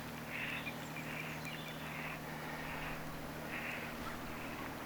haapananaaraan ääntelyä